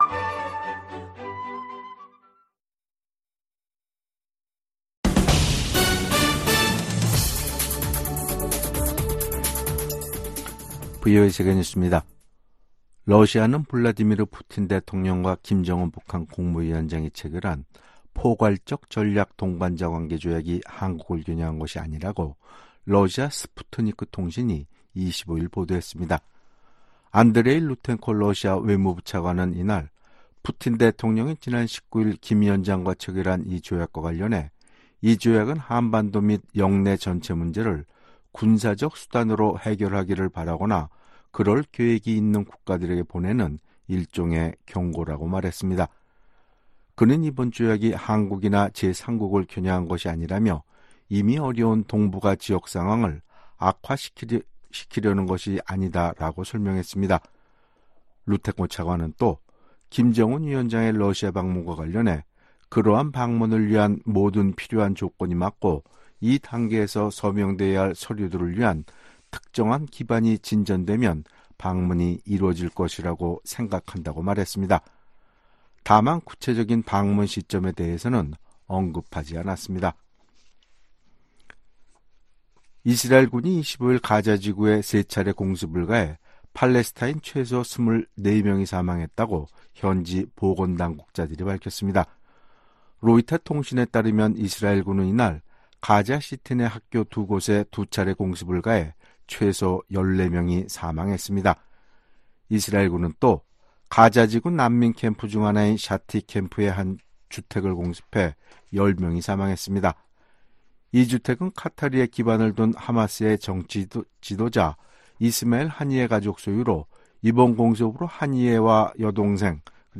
VOA 한국어 간판 뉴스 프로그램 '뉴스 투데이', 2024년 6월 25일 2부 방송입니다. 한국에 제공하는 미국의 확장억제 강화는 워싱턴 선언 이행으로 적절한 수준이라고 커트 캠벨 미 국무부 부장관이 말했습니다. 북한이 한국에 또 다시 '오물 풍선'을 살포하고 새로운 방식의 군사 도발 가능성도 내비쳤습니다.